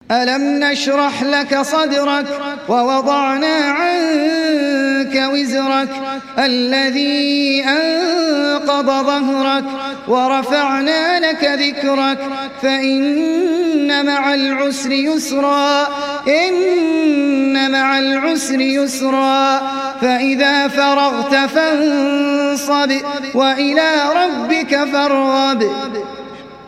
সূরা আশ-শারহ ডাউনলোড mp3 Ahmed Al Ajmi উপন্যাস Hafs থেকে Asim, ডাউনলোড করুন এবং কুরআন শুনুন mp3 সম্পূর্ণ সরাসরি লিঙ্ক